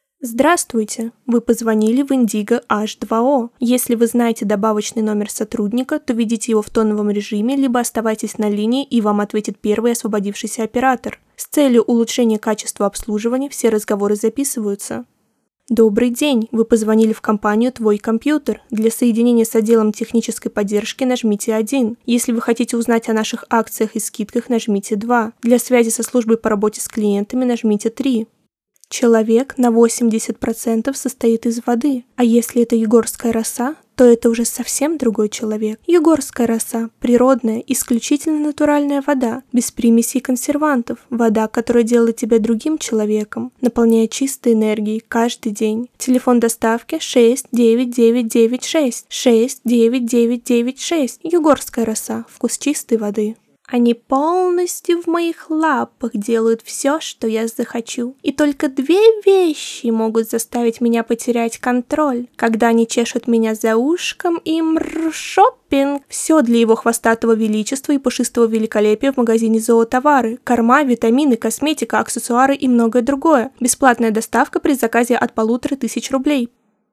Молодой приятный голос.